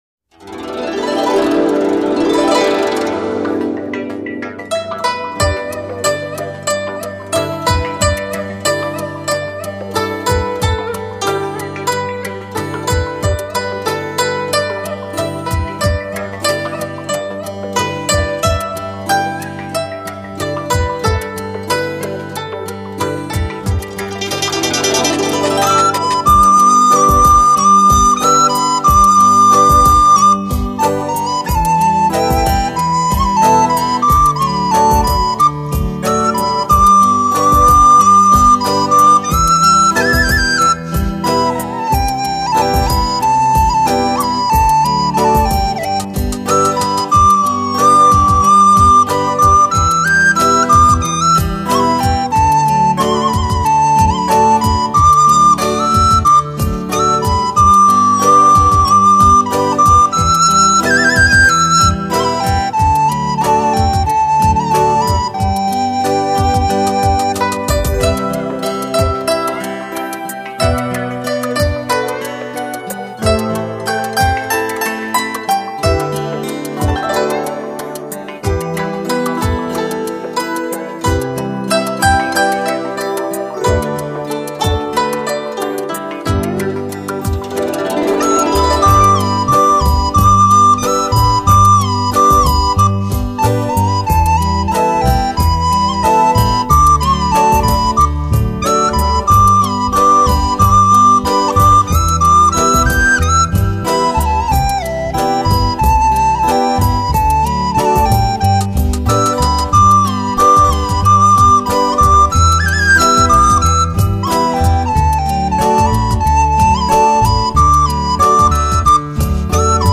浪漫新世纪音乐
※新世纪的编曲、优美的曲调，超完美的组合，
赋予传统直笛一种新的灵魂和意境。